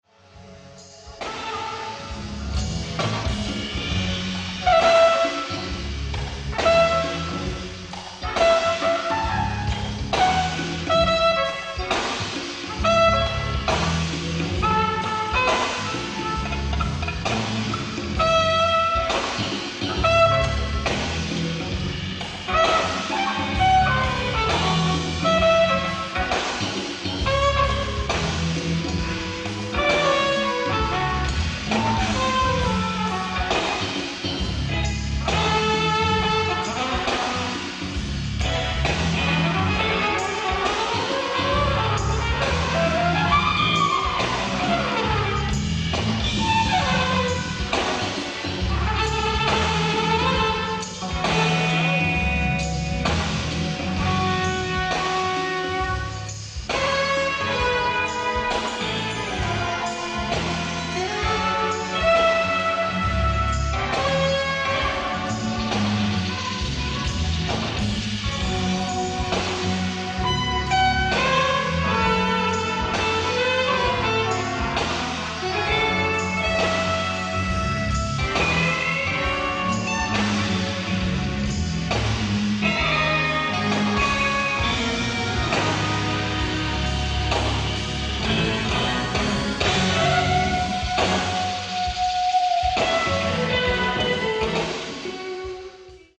ディスク１＆２：ライブ・アット・ビーコン・シアター、ニューヨーク 04/05/1986
※試聴用に実際より音質を落としています。